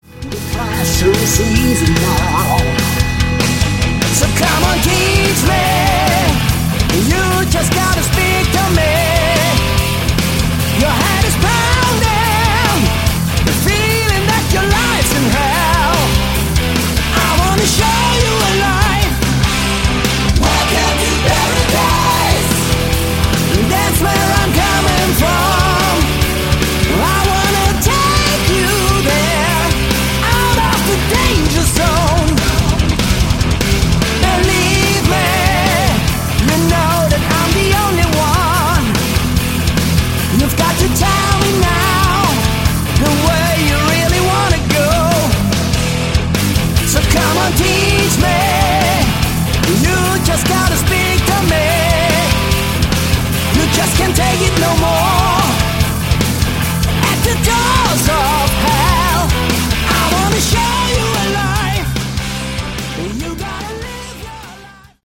Category: Melodic Rock
Vocals
Keyboards
Sax
Guitars
Drums
Bass